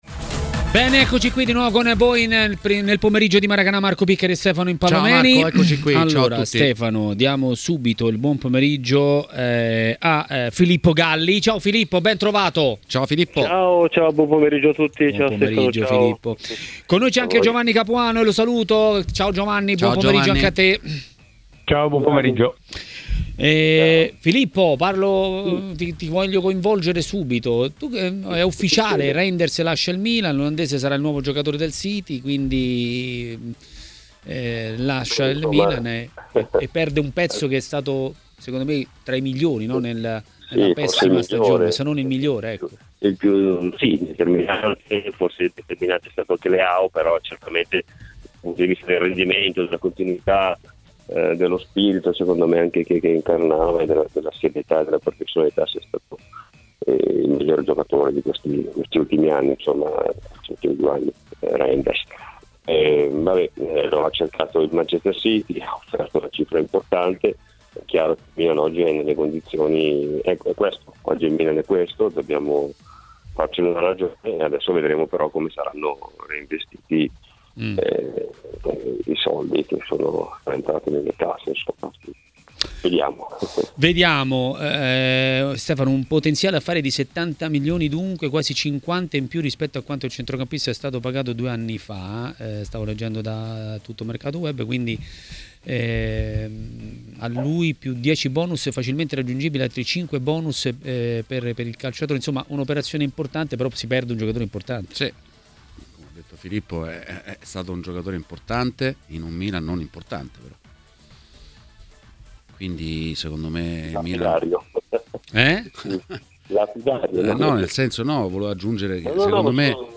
L'ex calciatore e dirigente Filippo Galli ha parlato dei temi del giorno a TMW Radio, durante Maracanà.